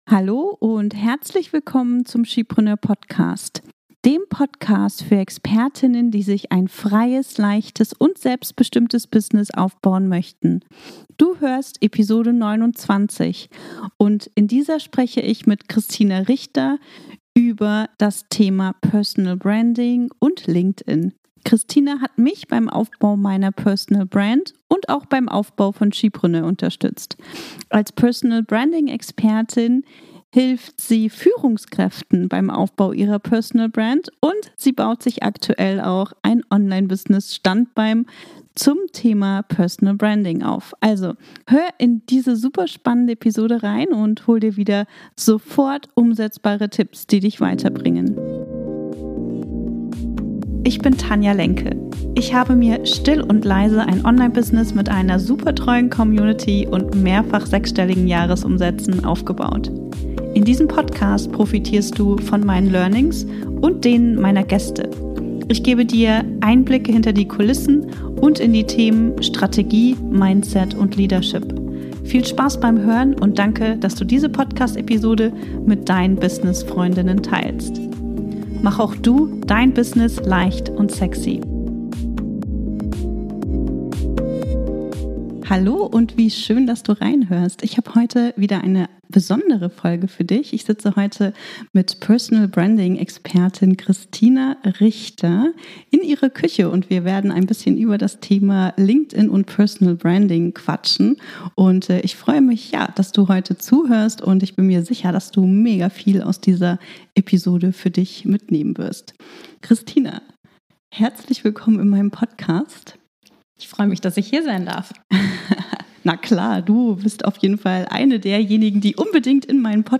029 - Was sagen andere über dich, wenn du nicht im Raum bist? Gespräch